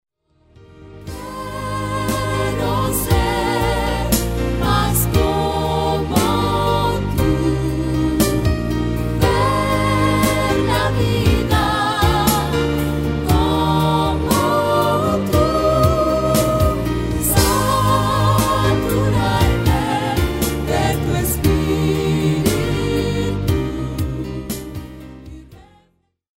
álbum clásico de adoración